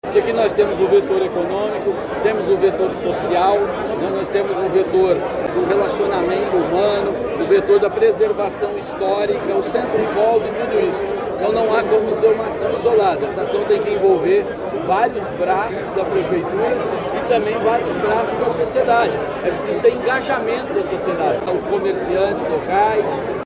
O vice-prefeito e secretário municipal do Desenvolvimento Econômico e Inovação, Paulo Martins, que vai coordenar a execução do Plano de Redesenvolvimento da Região Central de Curitiba, disse que o desafio é grande e as ações precisam ser multissetoriais.